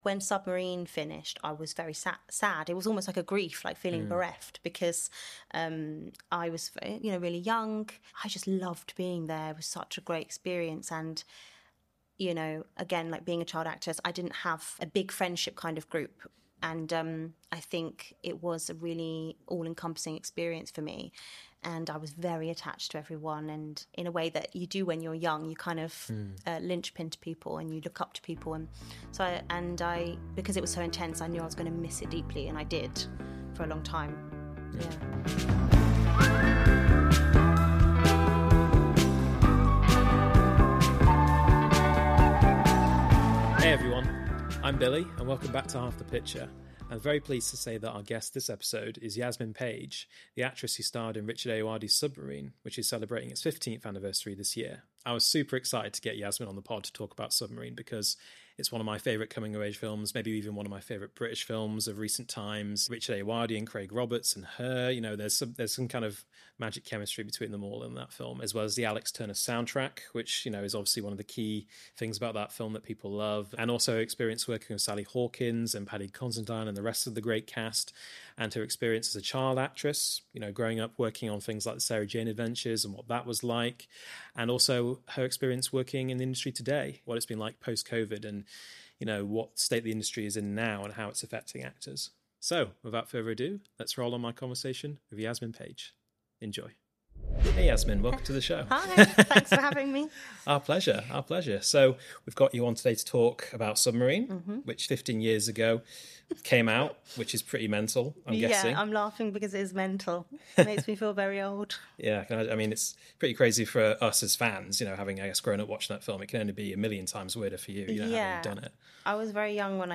Half the Picture’s guest this episode is actress Yasmin Paige, star of Richard Ayoade's cult classic coming of age movie, 'Submarine', celebrating it's 15th ann…